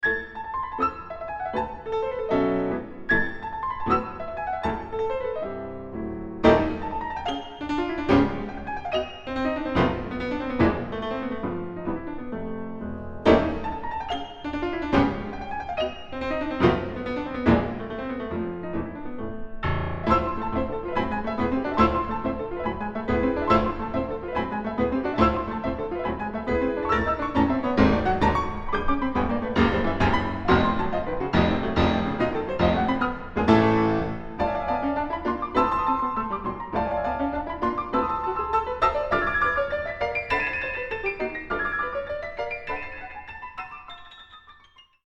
滋賀県立芸術劇場びわ湖ホール　小ホール
2台ピアノ